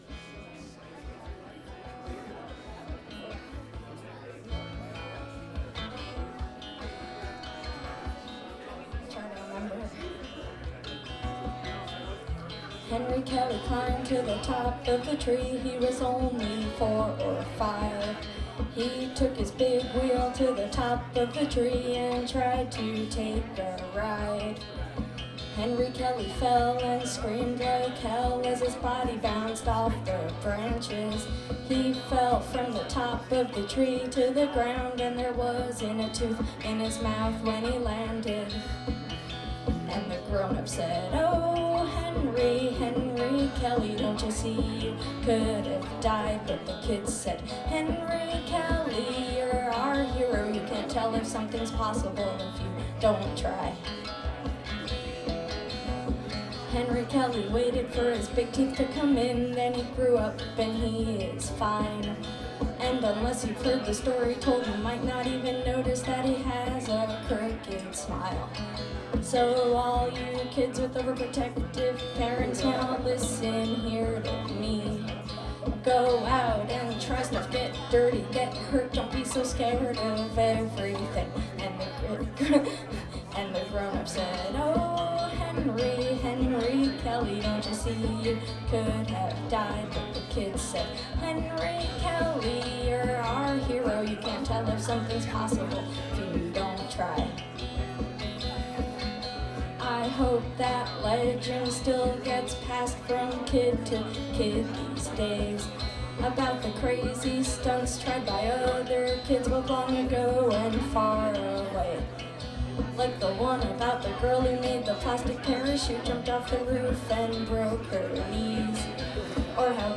Her solo set is very soothing and comforting to listen to.